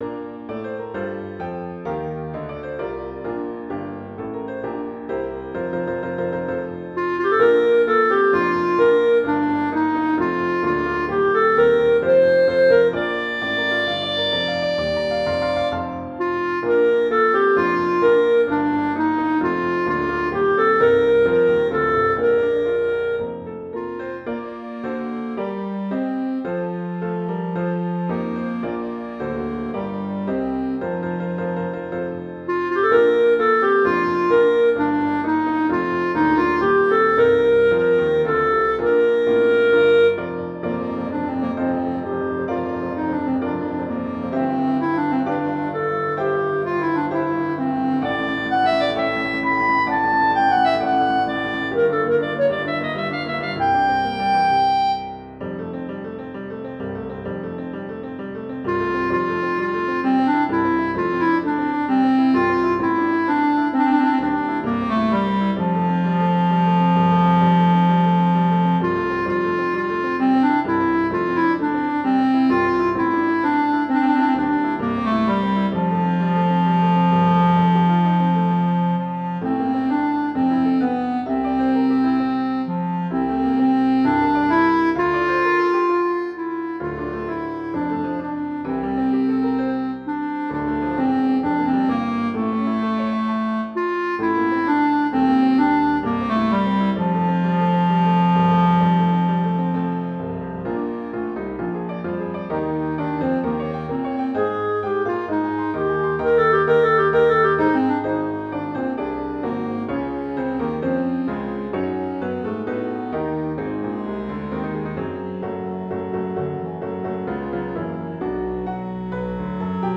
Intermediate Instrumental Solo with Piano Accompaniment.